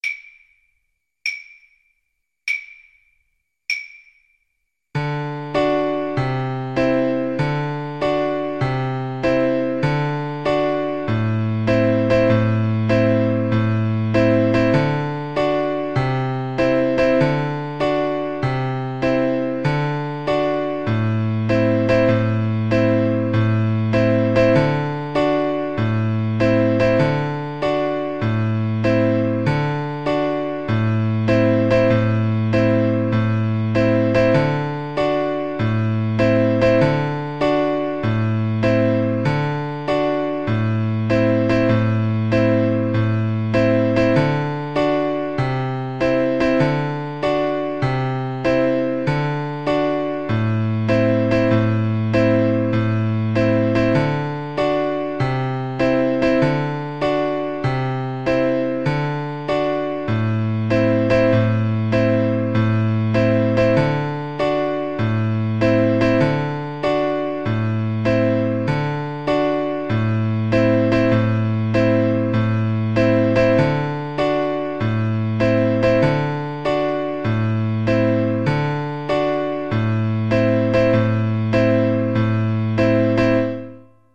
Play (or use space bar on your keyboard) Pause Music Playalong - Piano Accompaniment Playalong Band Accompaniment not yet available transpose reset tempo print settings full screen
A well-known traditional Irish folk tune, also known as Dinny Delany’s, The Hag At The Kiln, The Hag In The Kiln, Old Hag At The Kiln, The Old Hag At The Kiln, The Old Hag In The Kiln, The Old Hag.
D major (Sounding Pitch) (View more D major Music for Violin )
Poco meno mosso .=72
Traditional (View more Traditional Violin Music)